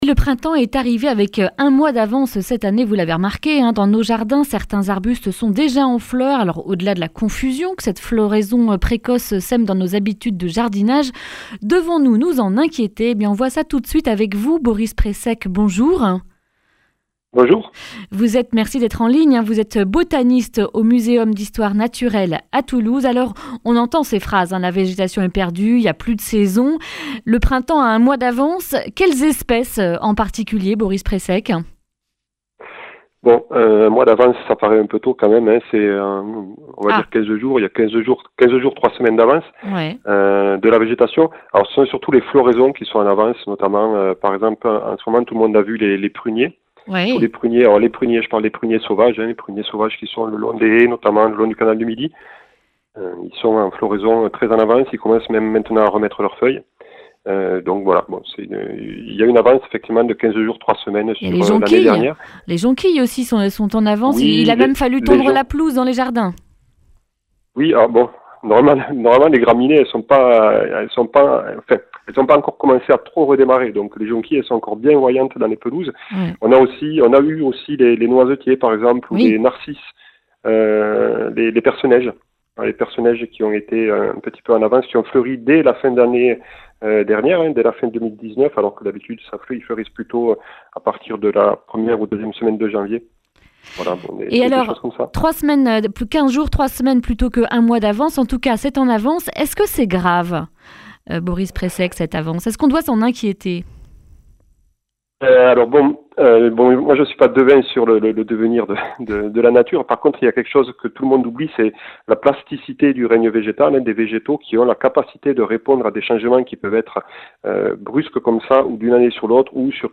mardi 3 mars 2020 Le grand entretien Durée 10 min